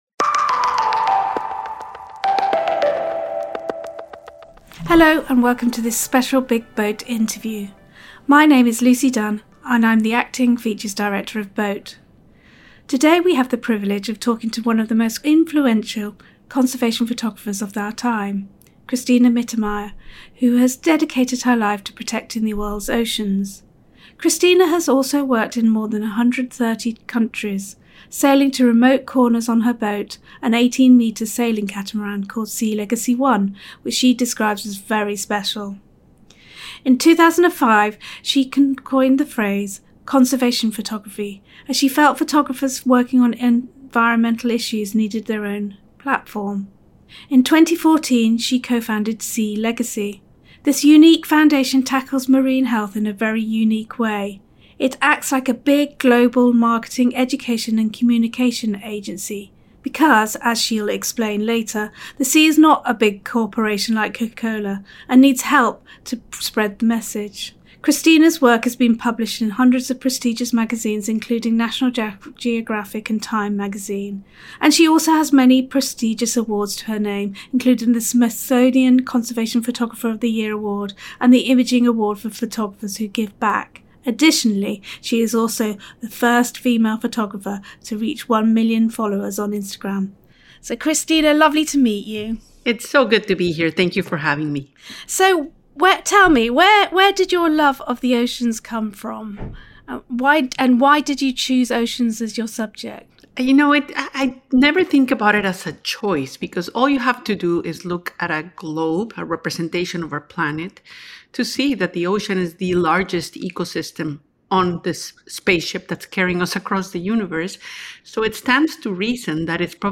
The Big BOAT Interview: Cristina Mittermeier